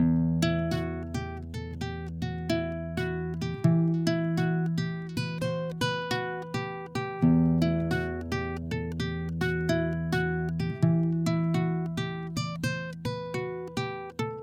描述：雅马哈C40尼龙吉他录音。
Tag: 古典 尼龙 声学吉他